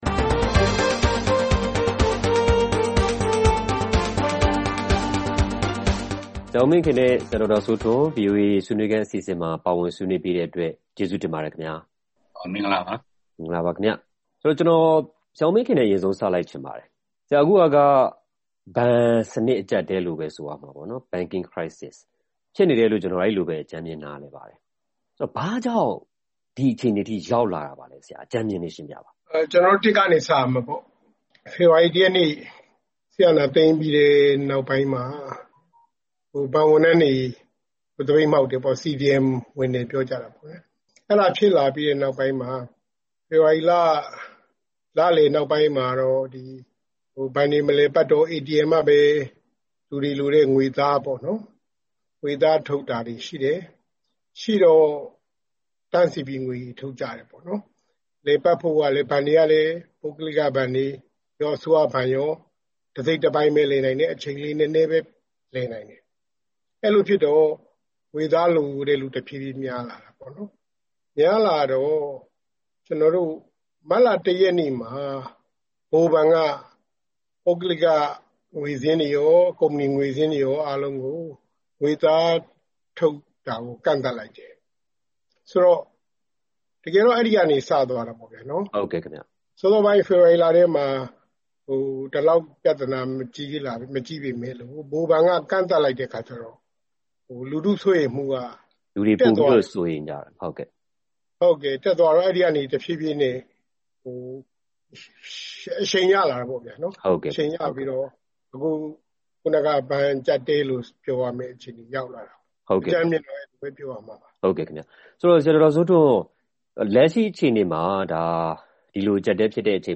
လက်ရှိ မြန်မာနိုင်ငံမှာ ကြုံတွေ့နေရတဲ့ ဘဏ်နဲ့ ငွေကြေးအကျပ်အတည်း ပြေလည်နိုင်ခြေရှိရဲ့လားဆိုတာ စီးပွားရေးပညာရှင်တဦး၊ လုပ်ငန်းရှင်တဦးတို့နဲ့ ဆွေးနွေးထားပါတယ်။